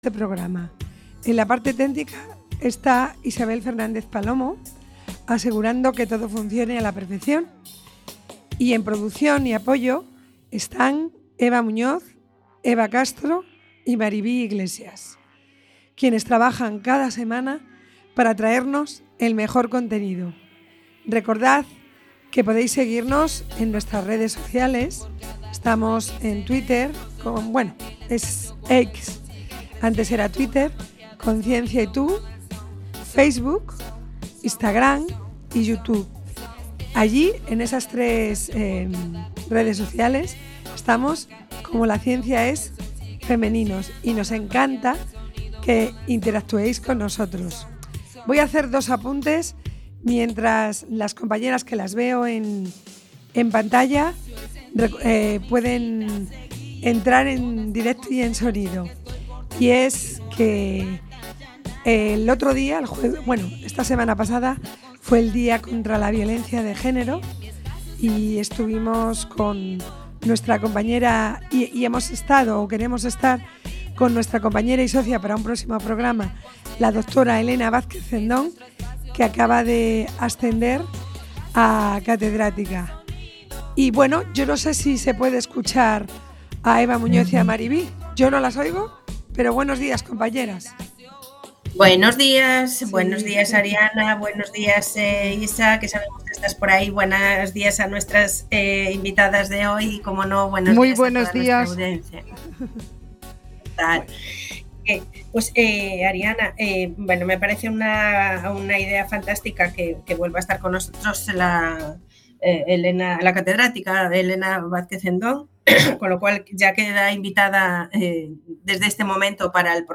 En este programa de radio, cada 15 días, durante una hora, de 12.00 a 13.00, en domingos alternos, Entrevistamos y damos voz a las científicas gallegas que trabajan hoy en la ciencia.